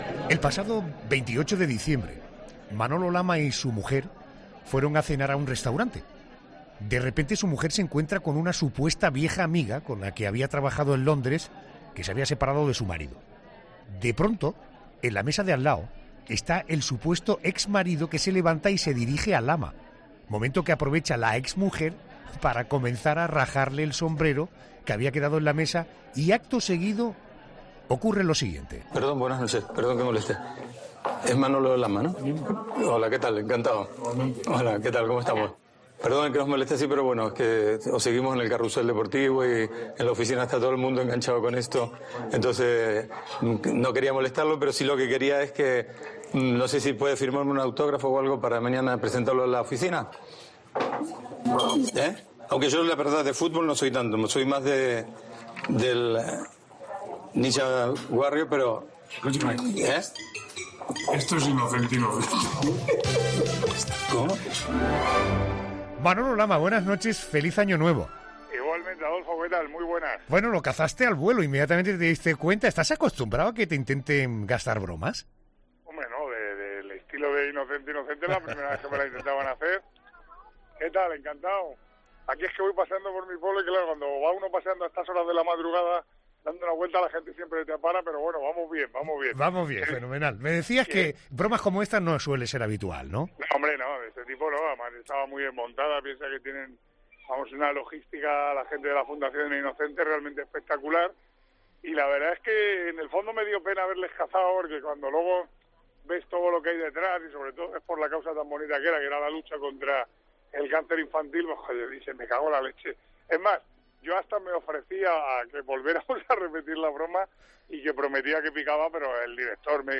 Adolfo Arjona aprovechó este miércoles en 'La Noche' para desearle un feliz y hablaron durante una amena charla sobre la actualidad deportiva, la Navidad y los Reyes Magos.